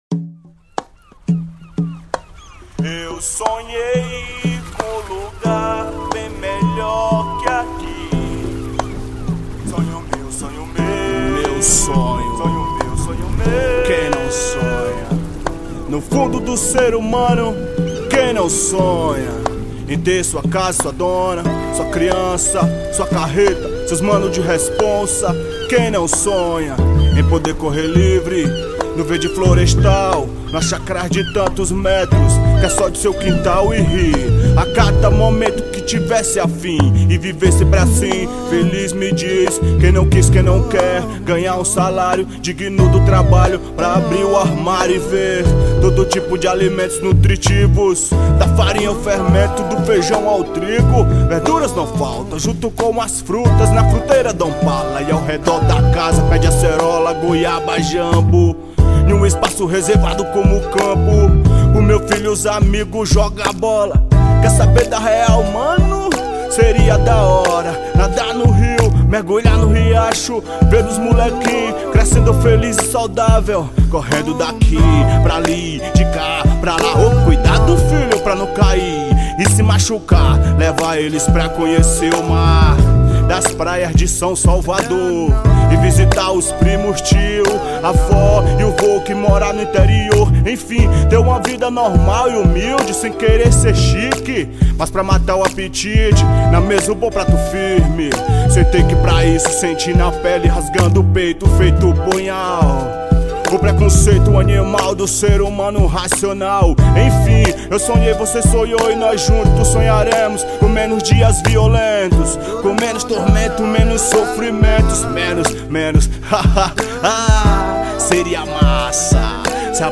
EstiloHip Hop / Rap